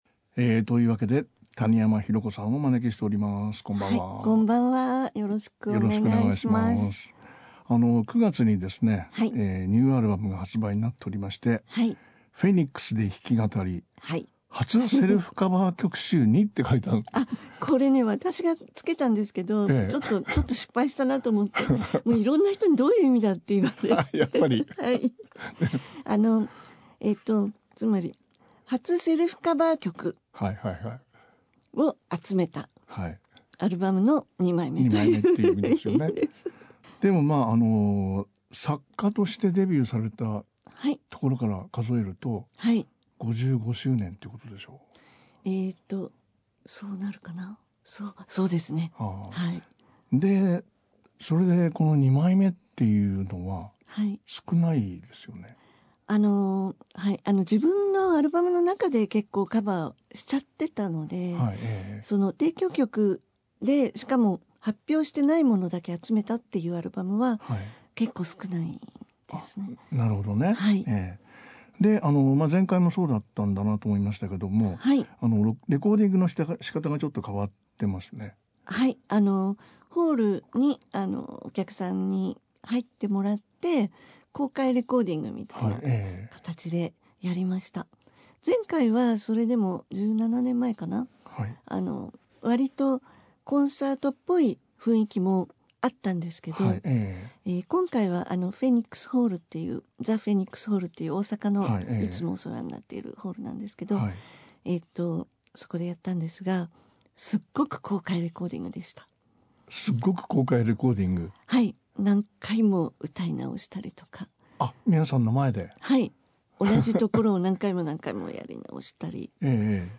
音楽評論家･田家秀樹が聞き出すアーティストの本音のインタビュー、J-POP界の裏話などJ-POPファンなら聞き逃せない魅力満載でお送りする30分。Podcastでは番組で放送した内容を「ほぼノーカット」でお送りしています。